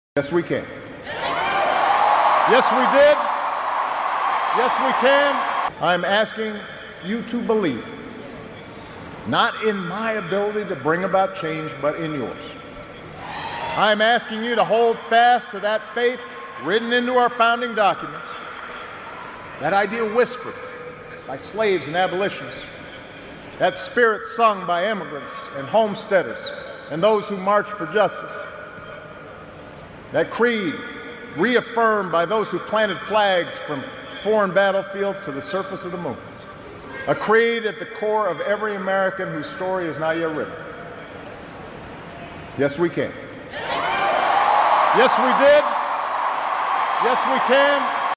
🗣🇺🇸 Barack Obama's iconic 'Yes We Can' speech! Relive the moment that inspired a generation and fueled a movement.